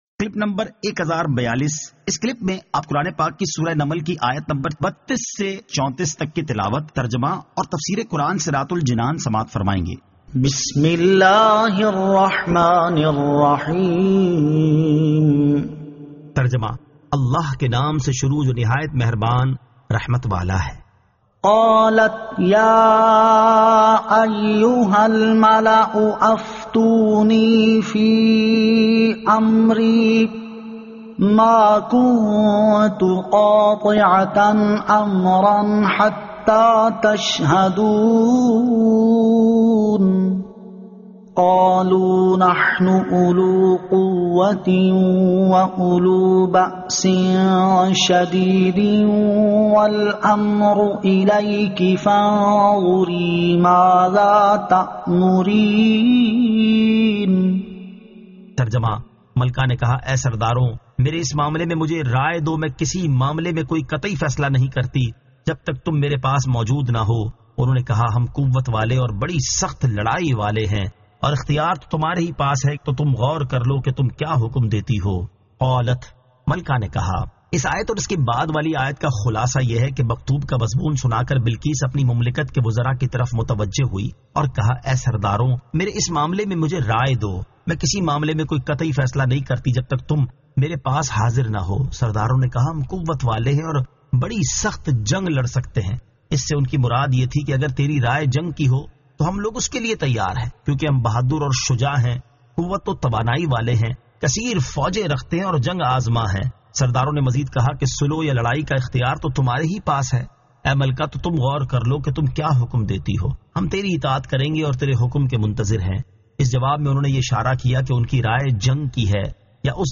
Surah An-Naml 32 To 34 Tilawat , Tarjama , Tafseer